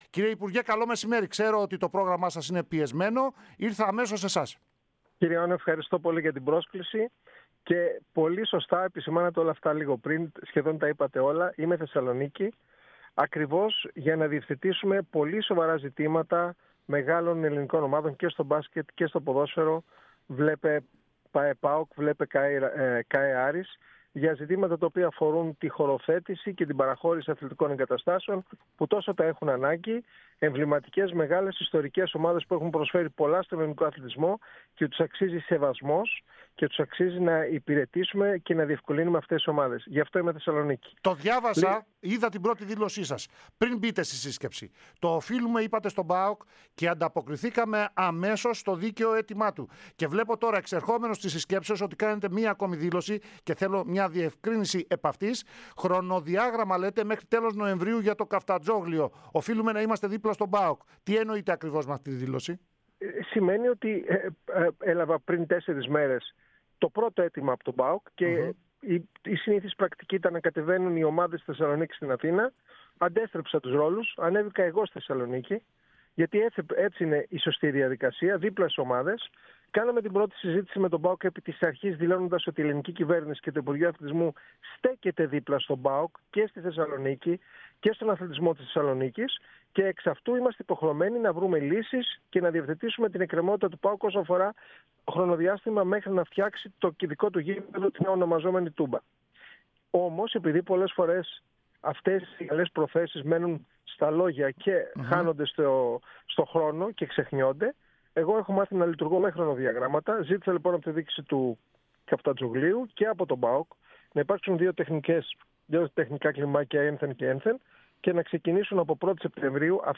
Αναλυτικά όσα δήλωσε ο αναπληρωτής Υπουργός Αθλητισμού στην ΕΡΑ ΣΠΟΡ: